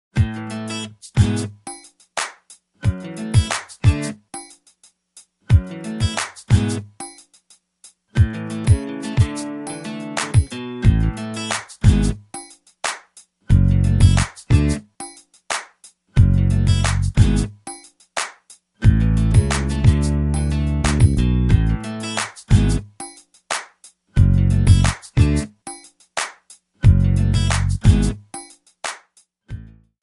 Backing track Karaoke
Pop, 2000s